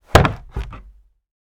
household
Cupboard Door Close 2